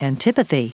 Prononciation du mot antipathy en anglais (fichier audio)
Prononciation du mot : antipathy